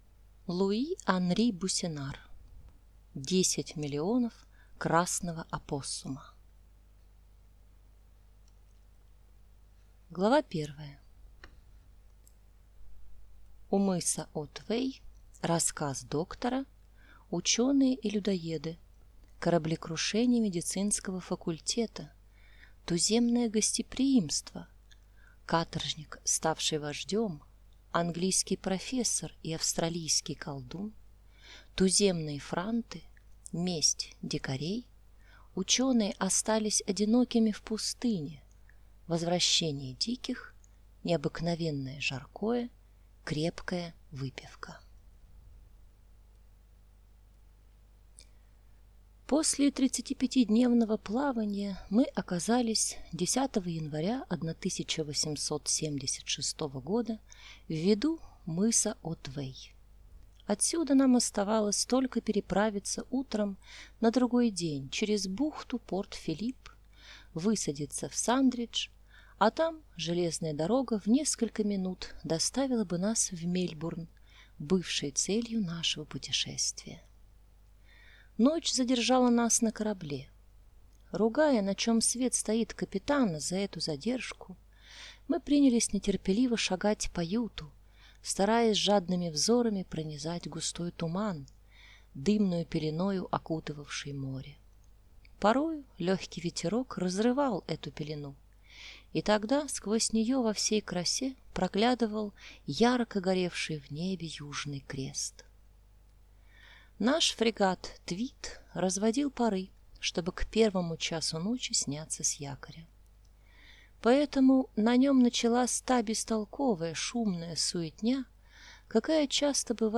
Аудиокнига Десять миллионов Красного Опоссума | Библиотека аудиокниг